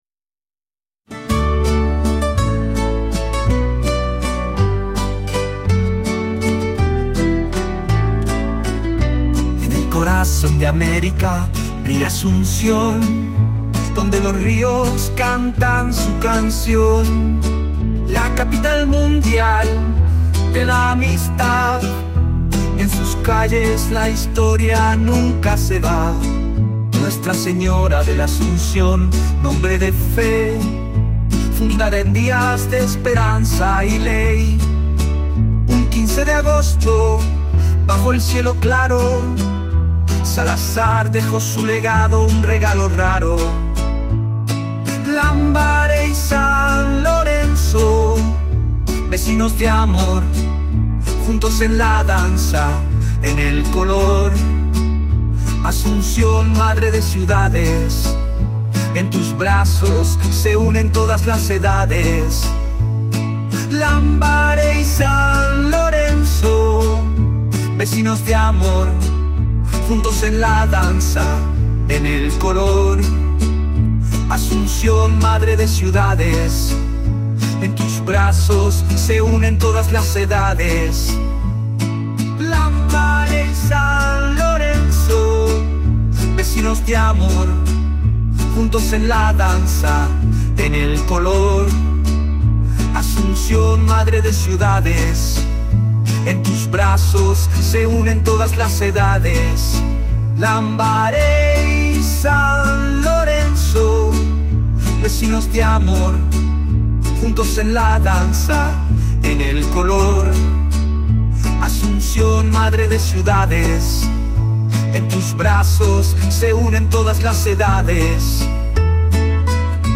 KI-Musik